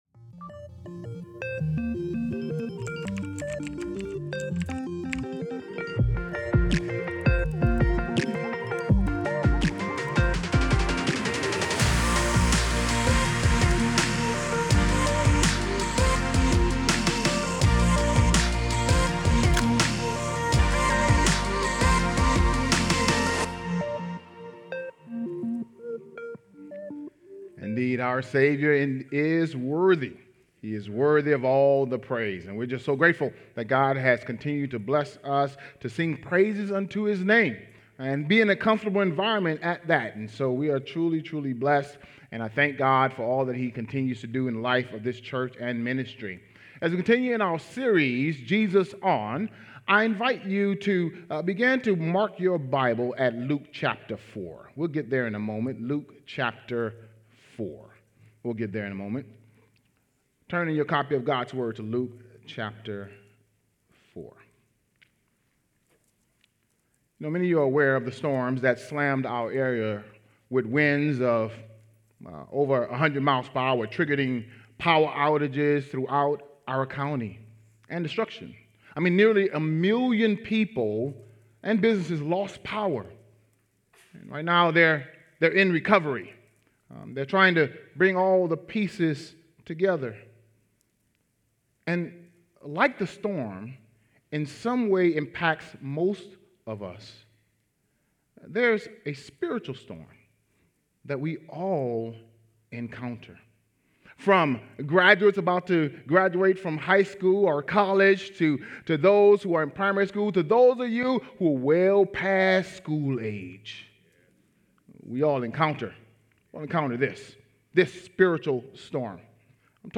JV-SERMON-ENG-Audio-5.19.24.mp3